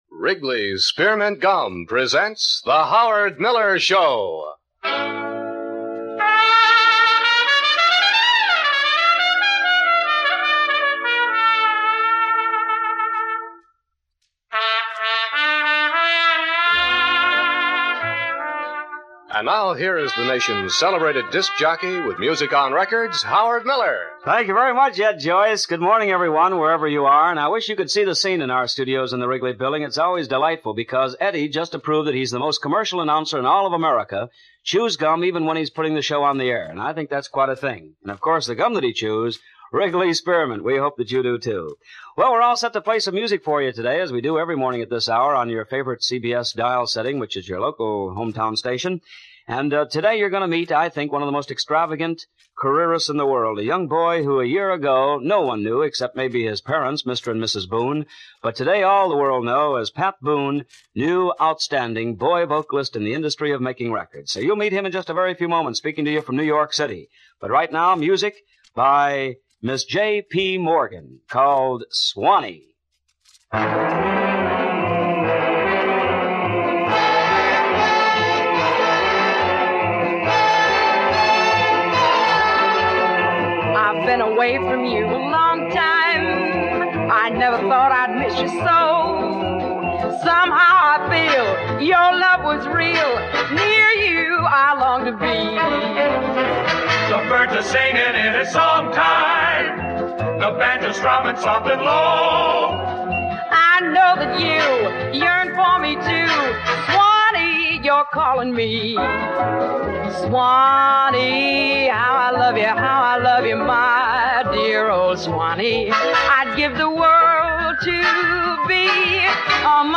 Pat Boone Talks About Rock N' Roll - 1955 - Past Daily Weekend Gallimaufry